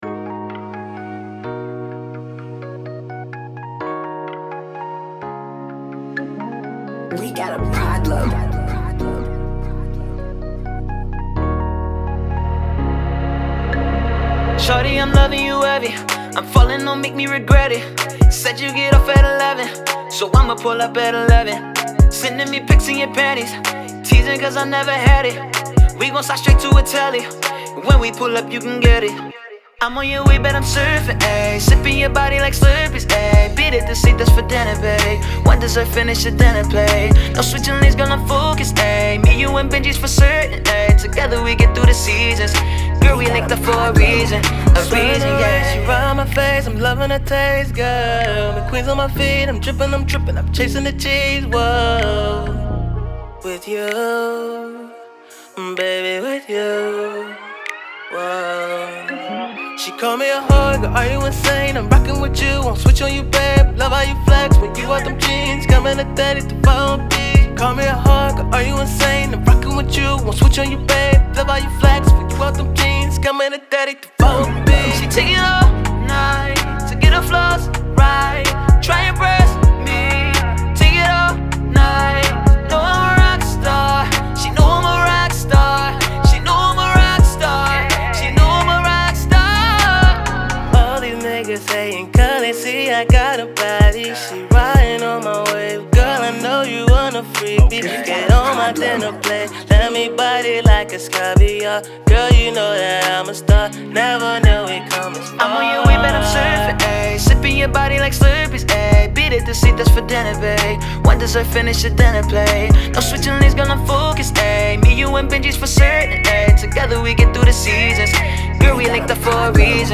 Pop
This Super talented group of two